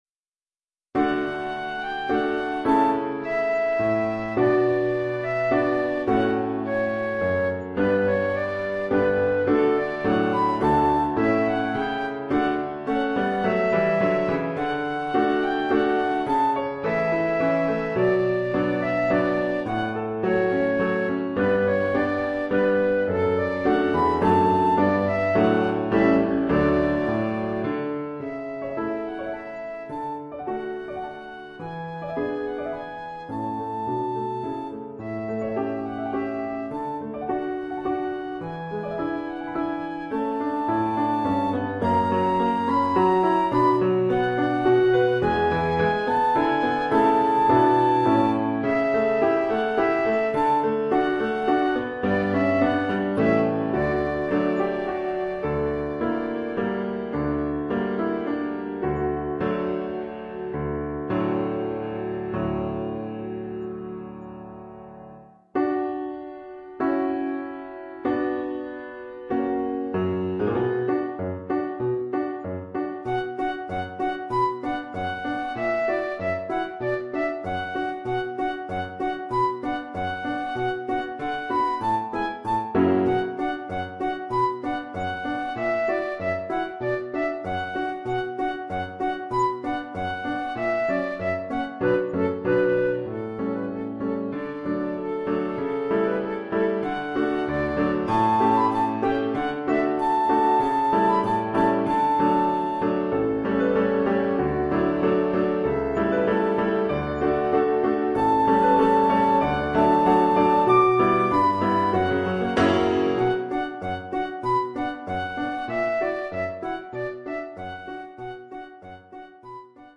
Oeuvre pour flûte et piano.